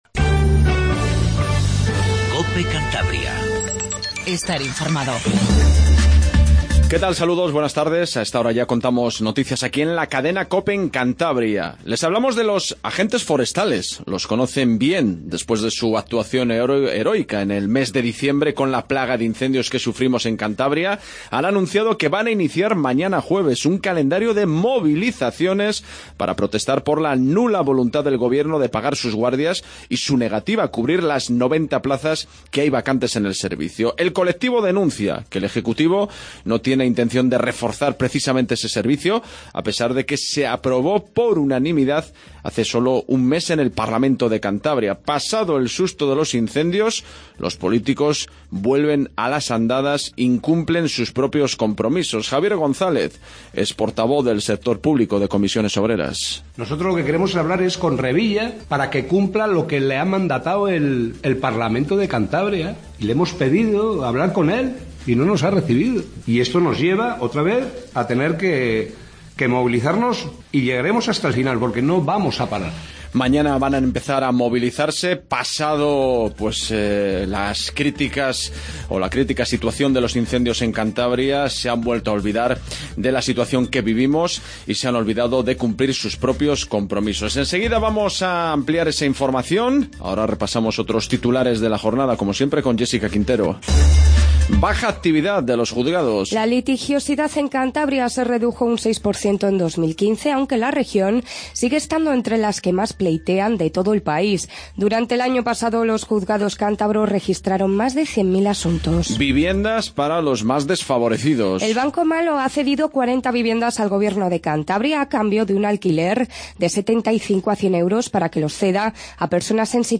INFORMATIVO REGIONAL 14:10